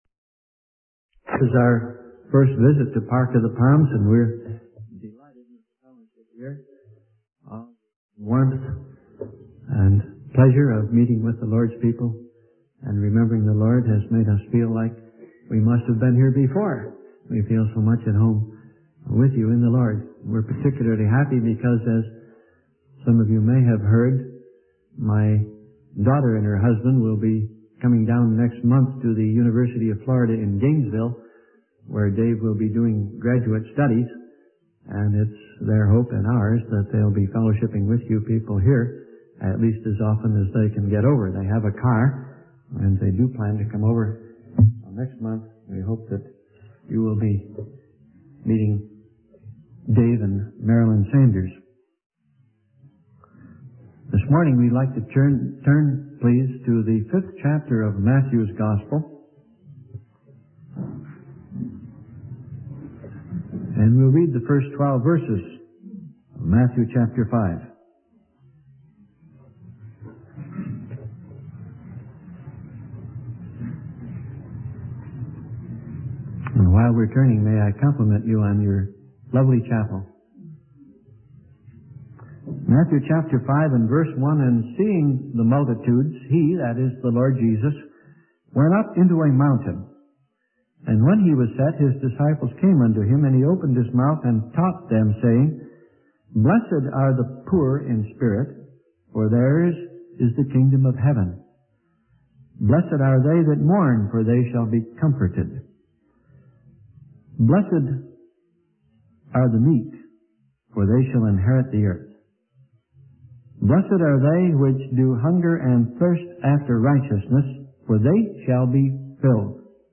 In this sermon, the preacher emphasizes the importance of being witnesses for God and teaching others the truths that He gives us. The sermon focuses on the Beatitudes, specifically the inward characteristics and outward manifestations of a follower of Christ. The preacher highlights the principle of starting out by being lovable and doing kind things for others in order to receive love and kindness in return.